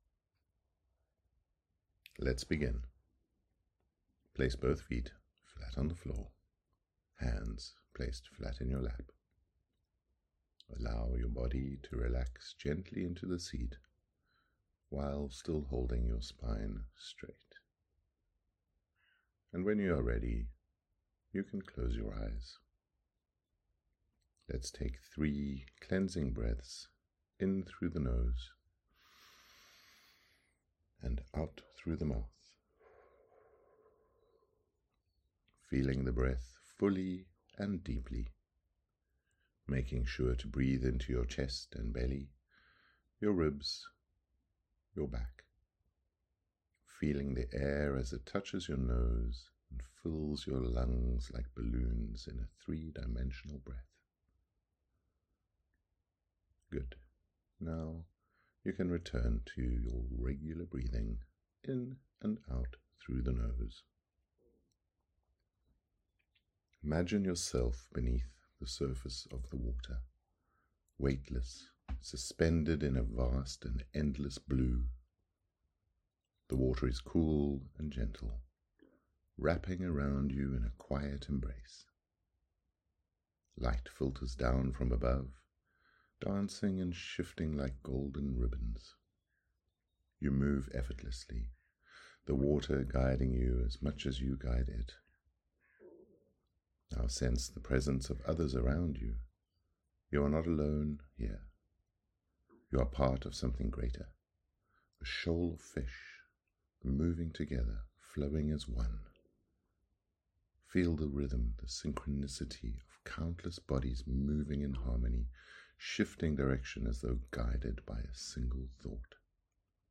fiNs in fOrmaTion Meditation
BB09-meditation-fiNs-in-fOrmaTion.mp3